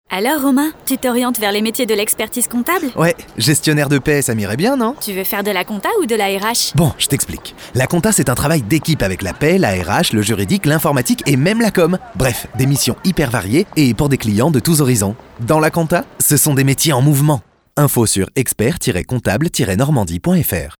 Spot 2